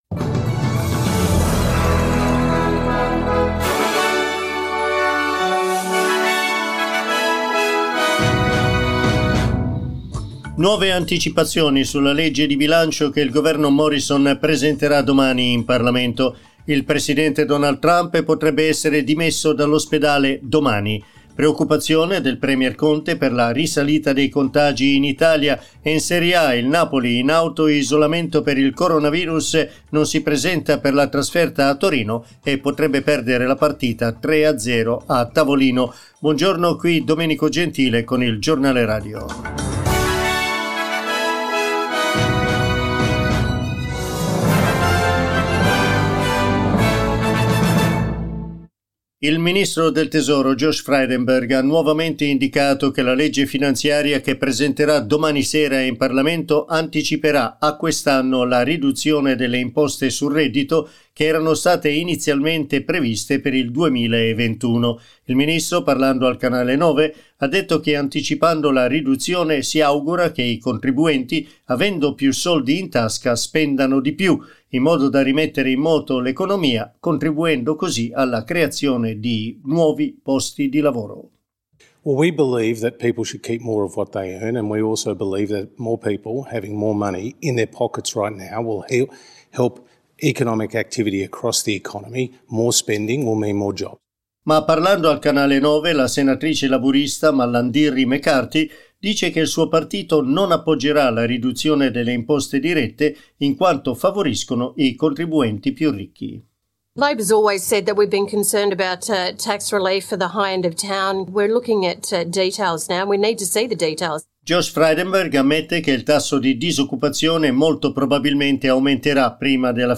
Il giornale radio di SBS Italian.